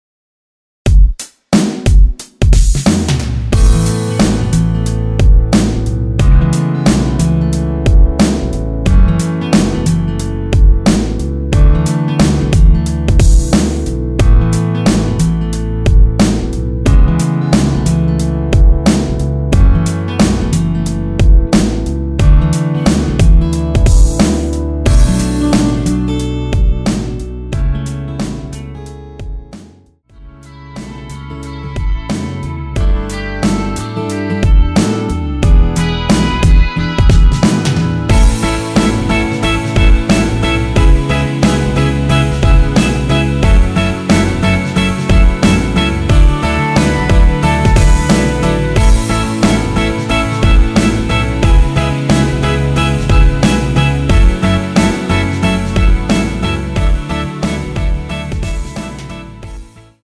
원키에서(-1) 내린 MR 입니다.
앞부분30초, 뒷부분30초씩 편집해서 올려 드리고 있습니다.
중간에 음이 끈어지고 다시 나오는 이유는